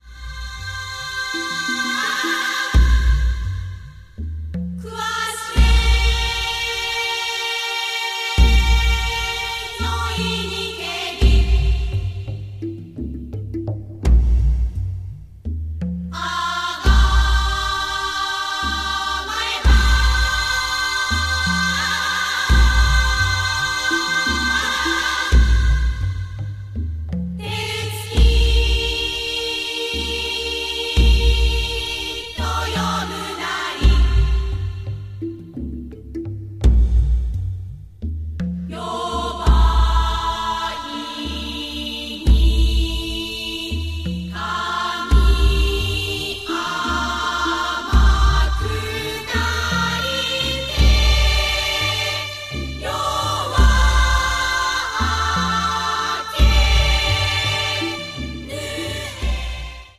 haunting score